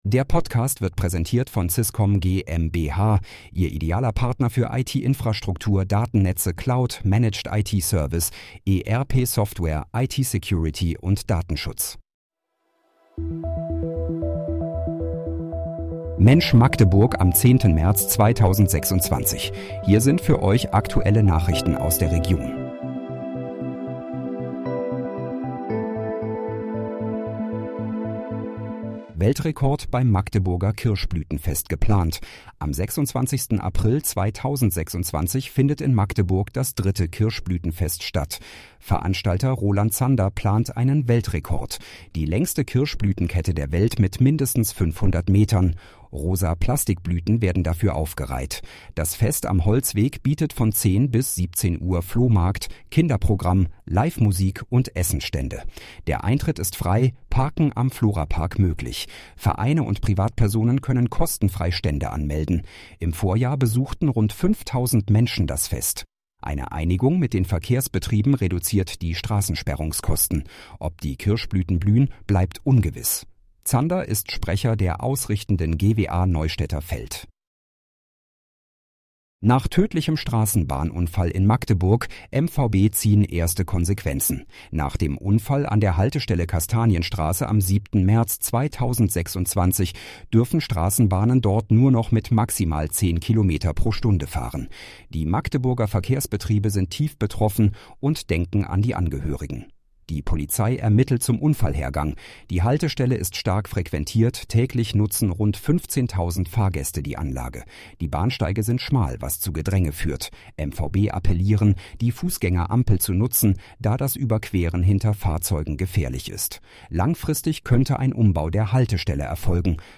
Mensch, Magdeburg: Aktuelle Nachrichten vom 10.03.2026, erstellt mit KI-Unterstützung